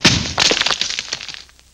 Plaster Falls Off Wall